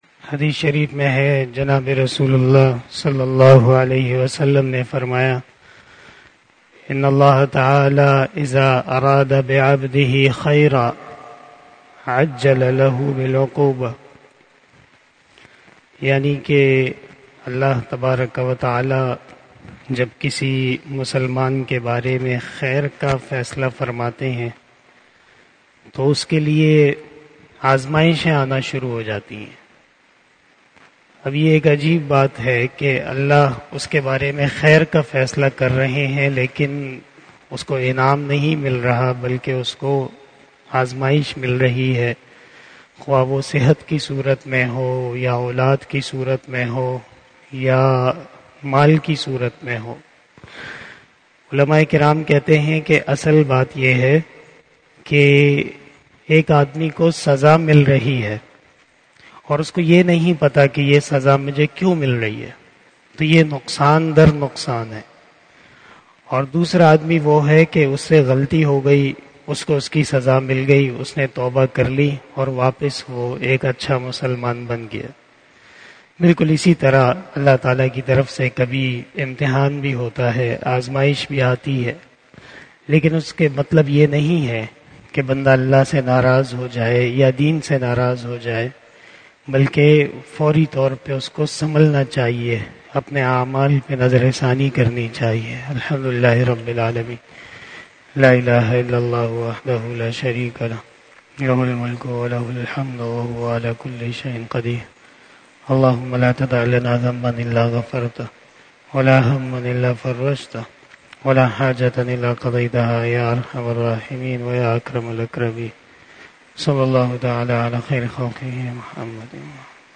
085 After Asar Namaz Bayan 18 September 2022 (21 Safar 1444HJ) Sunday